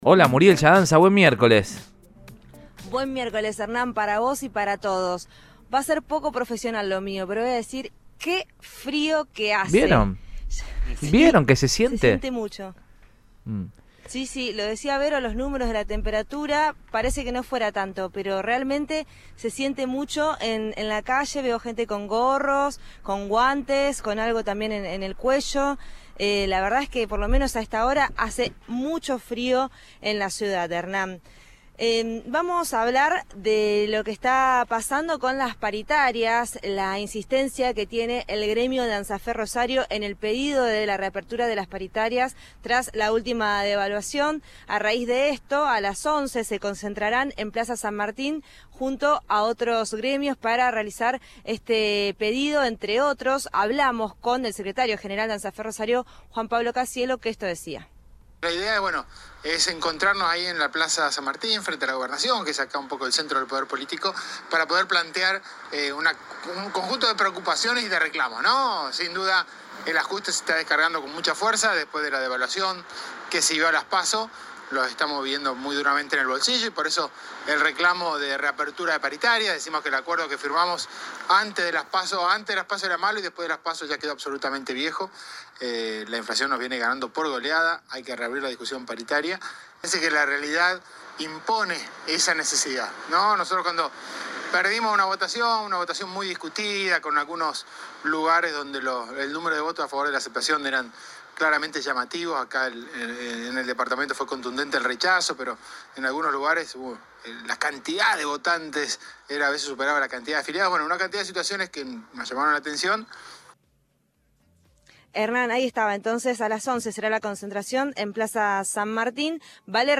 habló con el móvil de Cadena 3 Rosario, en Radioinforme 3